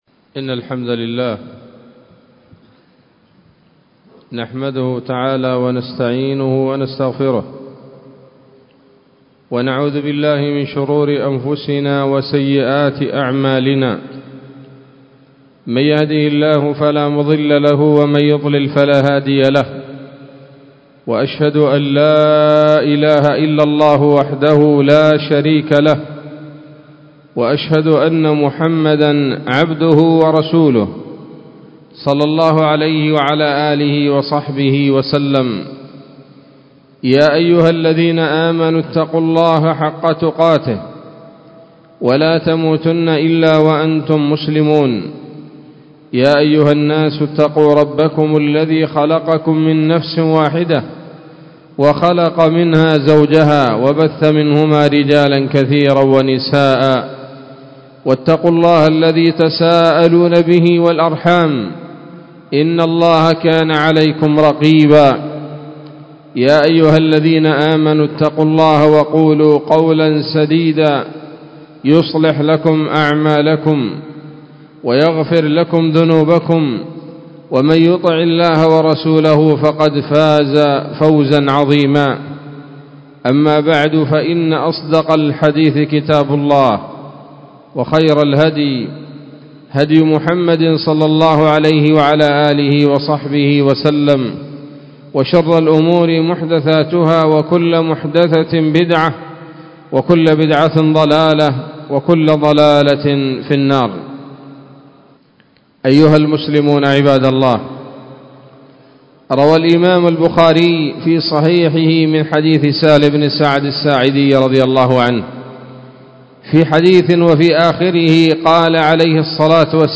خطبة جمعة بعنوان: (( الليلة المباركة )) 23 رمضان 1444 هـ، دار الحديث السلفية بصلاح الدين